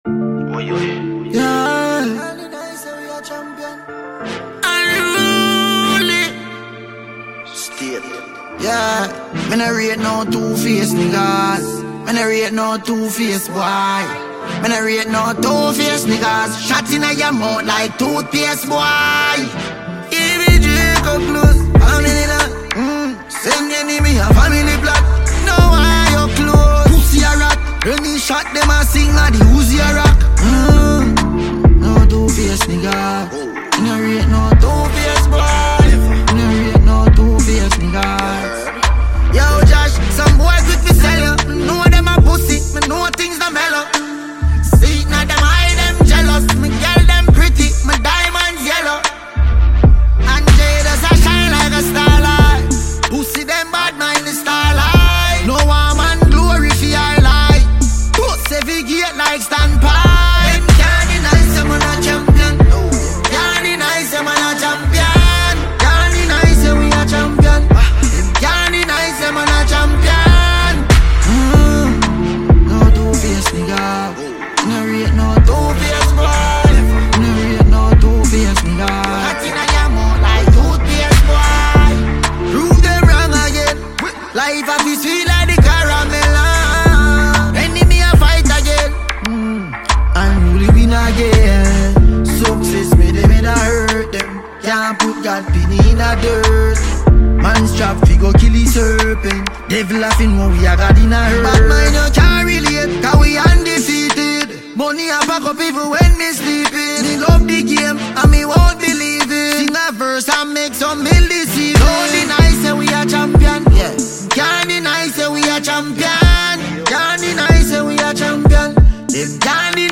Jamaican award-winning dancehall musician